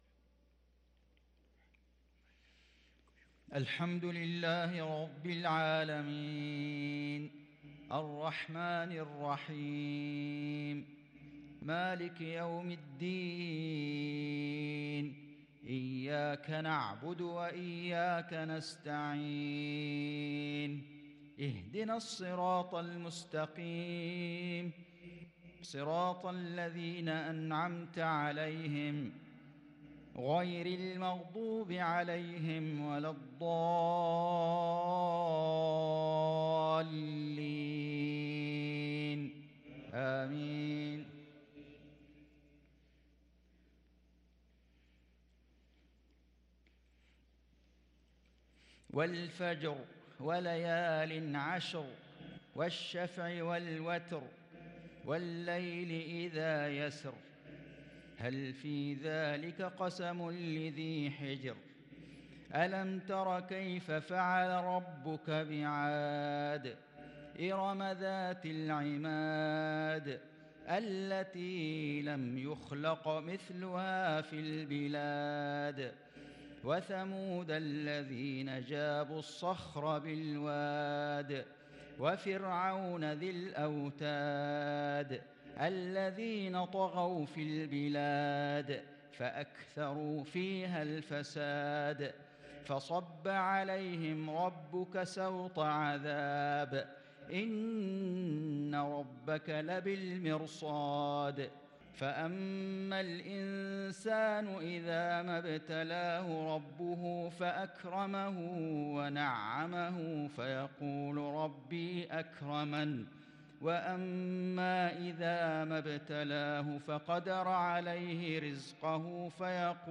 صلاة العشاء للقارئ فيصل غزاوي 13 جمادي الآخر 1443 هـ